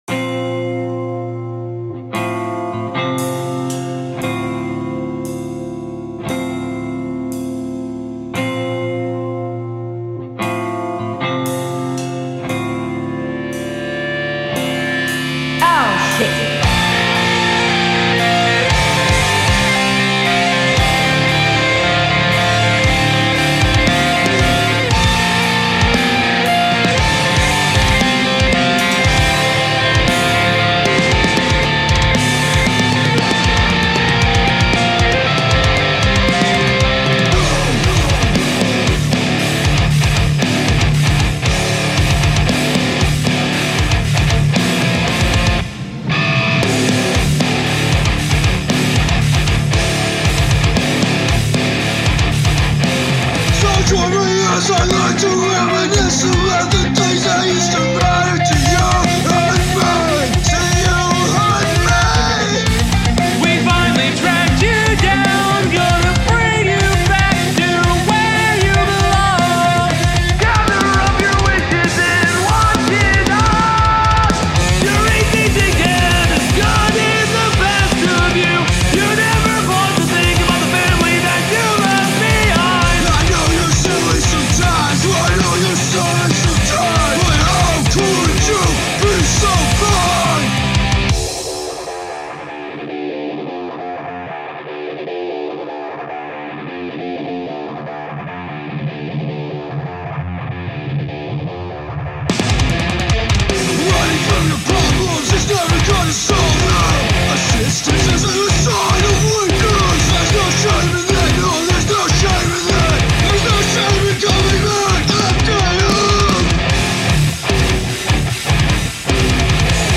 post-hardcore
Let me know if you want a version w/o the cartoon vocals.